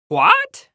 На этой странице собрана коллекция звуков, сопровождающих мелкие проблемы и досадные недоразумения.